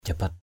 /ʥa-bat/ (đg.) lạy = se prosterner. kowtow. jabat sulam jbT s~l’ kính lạy = se prosterner respectueusement. kowtow respectfully. krân pajip tabiak anâk blaoh jabat sulam Déwa Mano (DWM) k;N...